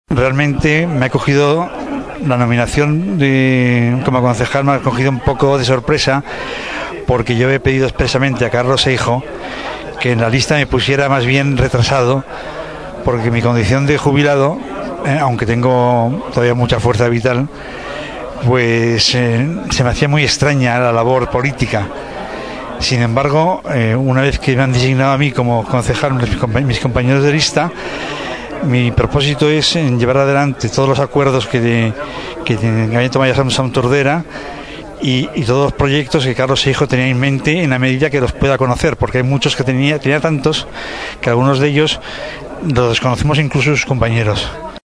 En declaracions a Ràdio Tordera, José Carlos Villaro explicava que l’opció de ser regidor el va agafar per sorpresa.